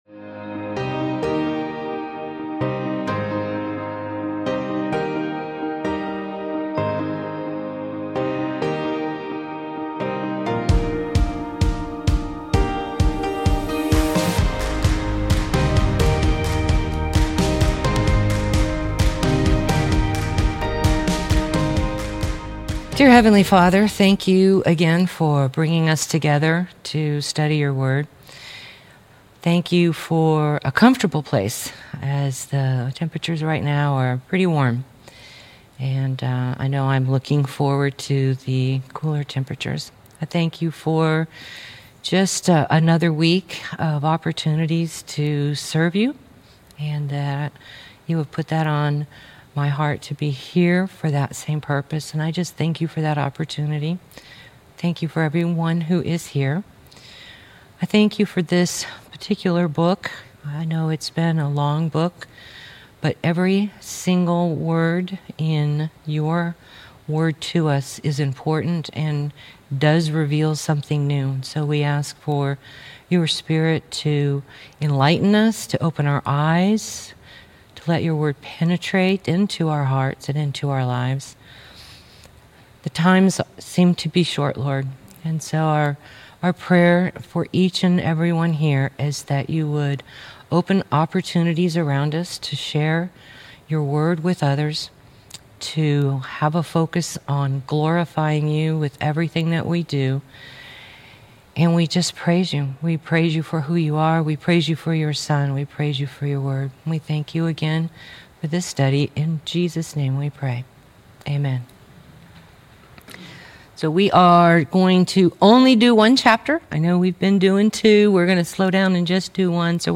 Jeremiah - Lesson 41 | Verse By Verse Ministry International